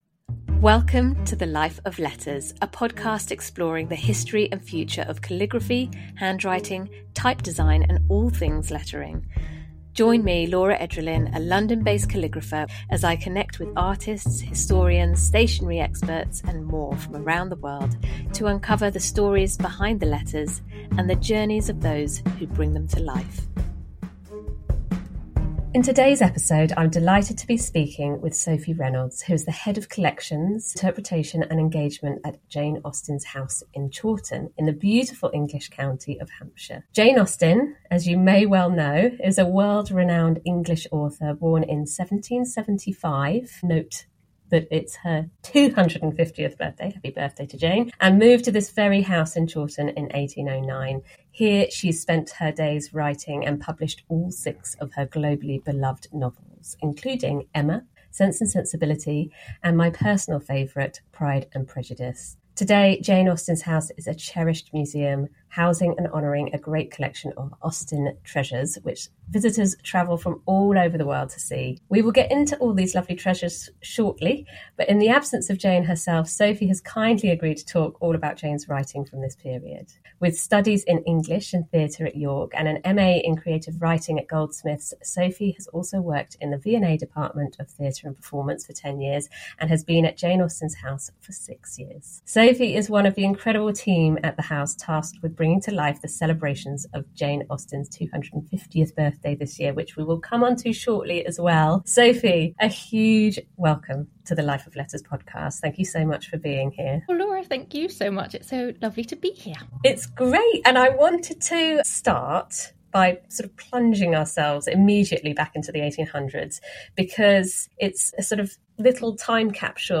They explore Austen's early writing, her journey to becoming a celebrated author, and the impact of her novels on literature and society. The conversation delves into Austen's writing process, the materials she used, and the art of letter writing during her time.